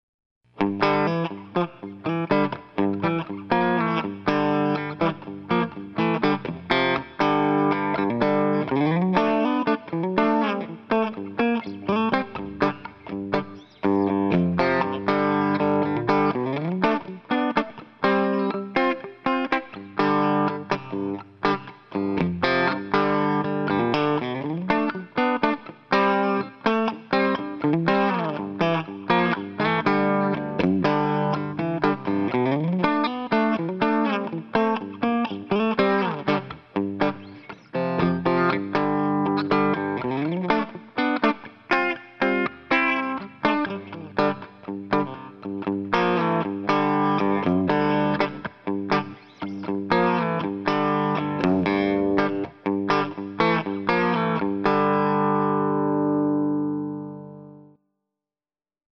Sound Sample of some of the best made Mosdern Vintage amps made today.
Clean_Tele.mp3